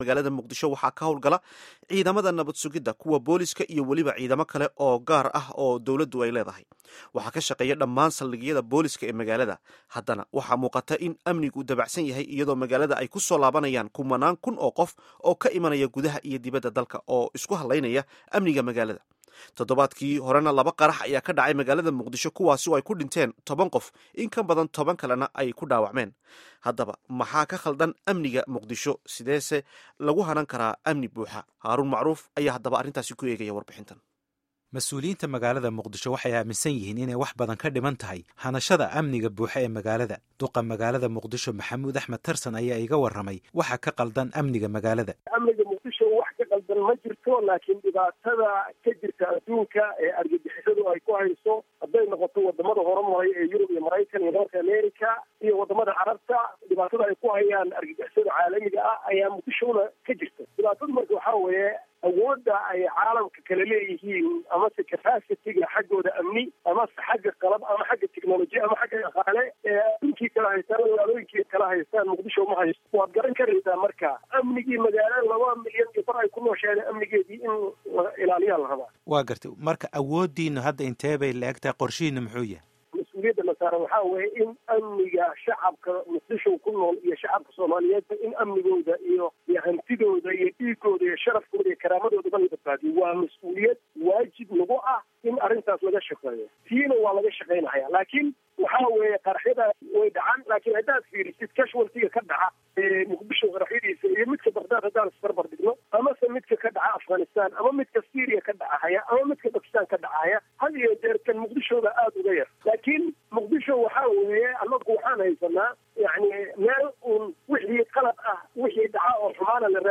Warbixin: Amniga Muqdisho